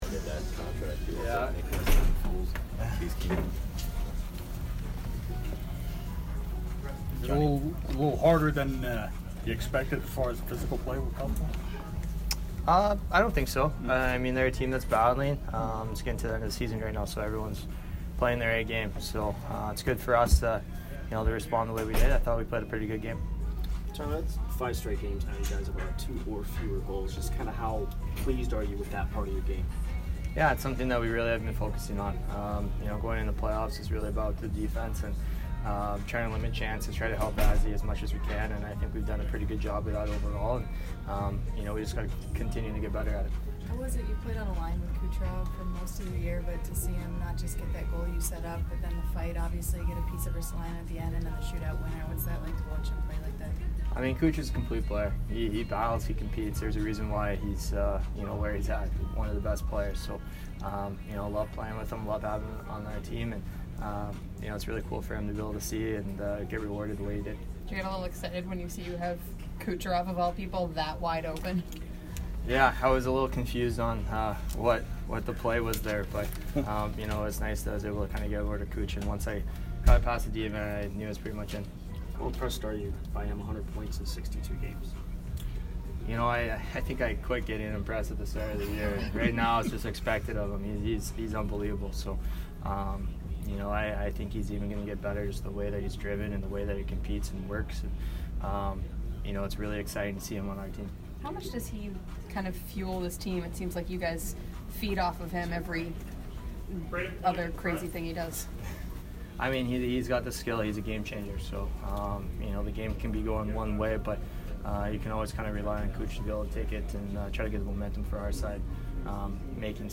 Tyler Johnson post-game 2/21